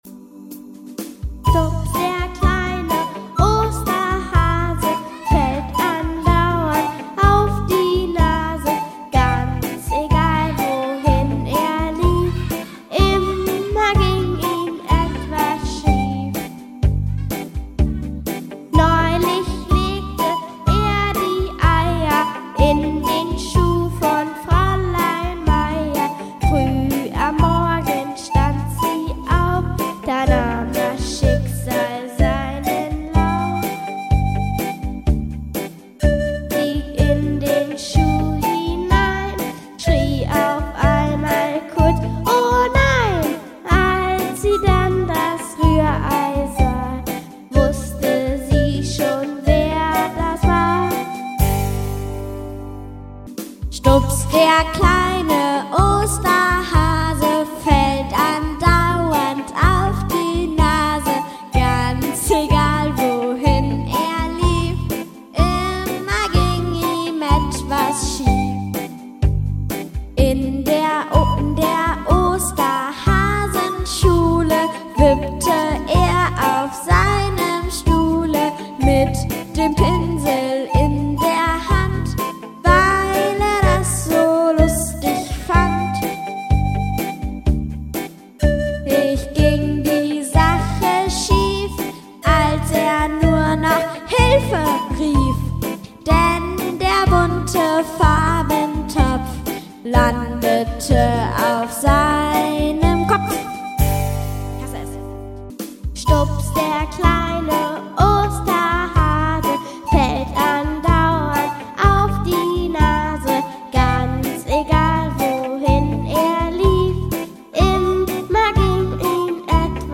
Blockflötengruppe
Wir spielen in G - Dur